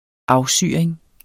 Udtale [ ˈɑwˌsyˀɐ̯eŋ ]